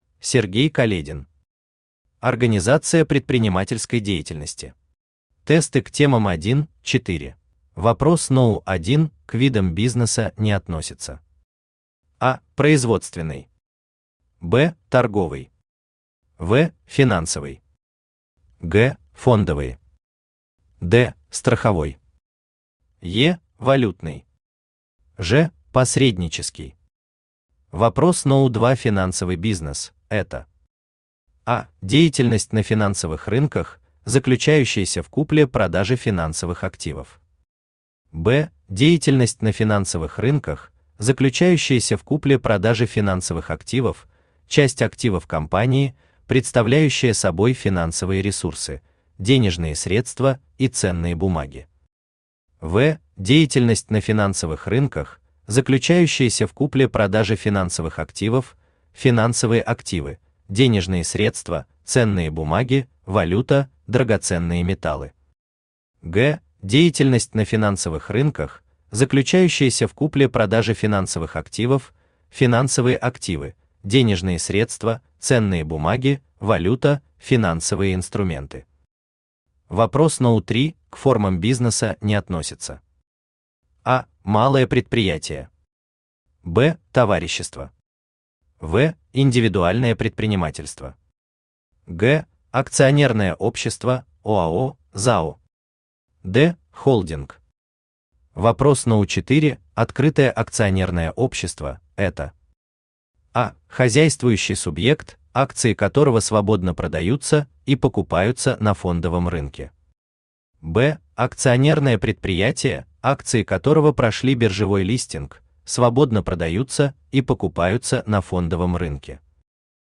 Тесты к темам 1-4 Автор Сергей Каледин Читает аудиокнигу Авточтец ЛитРес.